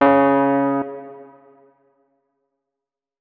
electric_piano
notes-25.ogg